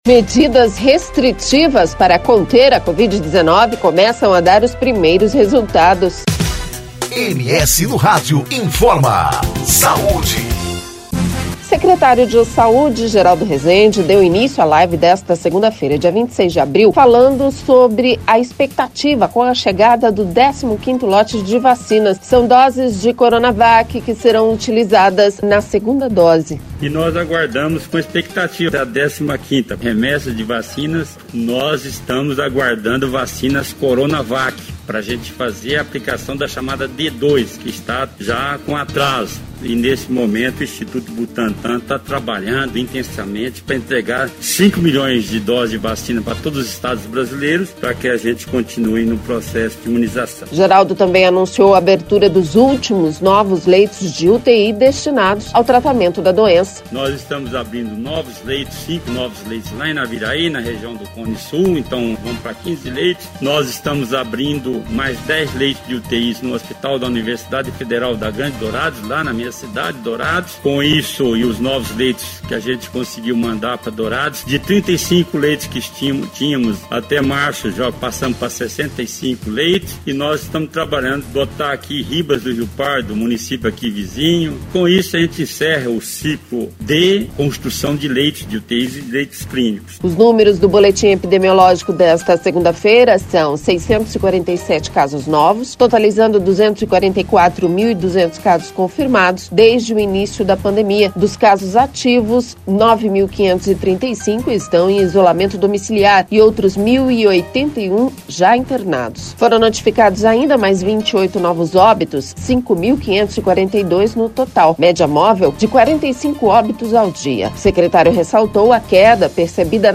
O secretário de saúde, Geraldo Resende deu início a live desta segunda-feira, dia 26, falando sobre a expectativa com a chegada do 15º lote de vacinas.